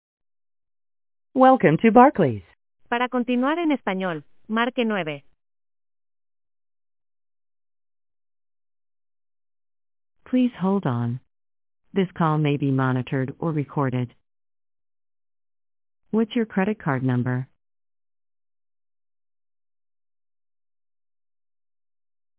THE REAL BARCLAYS BANK CREDIT CARD NUMBER MENUS ALL SOUND LIKE THIS